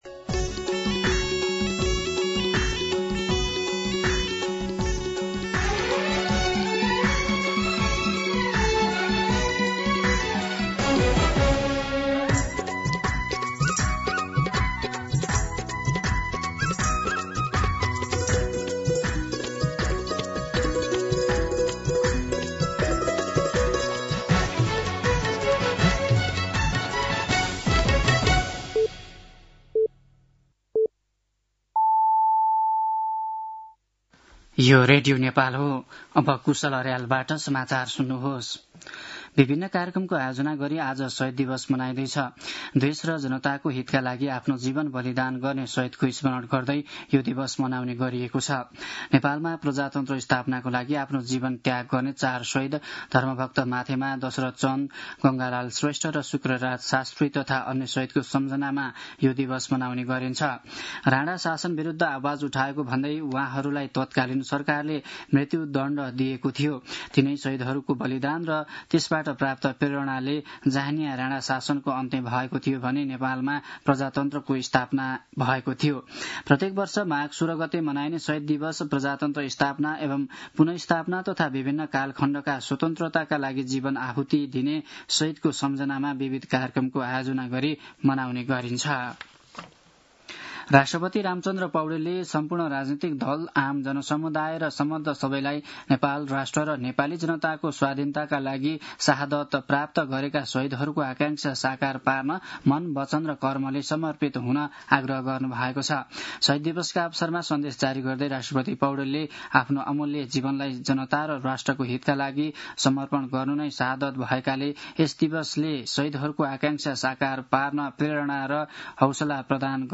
दिउँसो ४ बजेको नेपाली समाचार : १६ माघ , २०८२